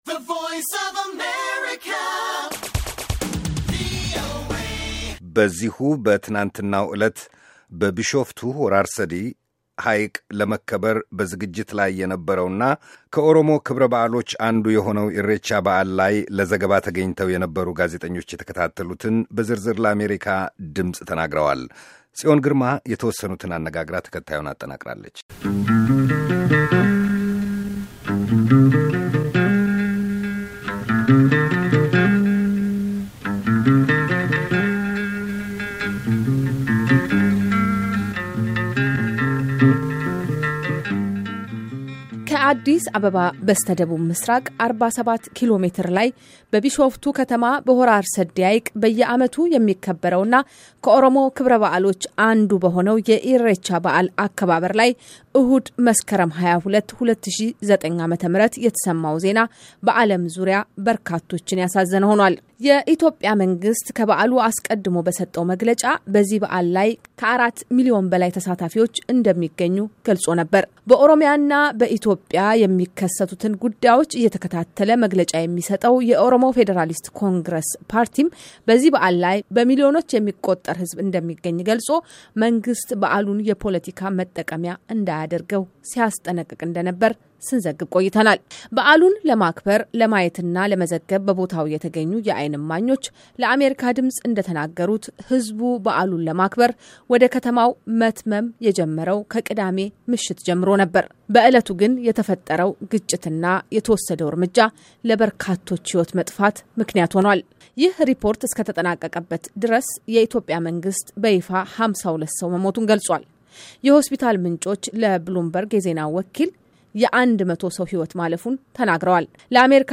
የበርካቶች ሕይወት በጠፋበት የኢሬቻ በዓል አከባበር ላይ በአካል ከነበሩ ጋዜጠኞች ጋር የተደረገ ቃለ ምልልስ